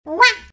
yoshi_Wa_Take_1.ogg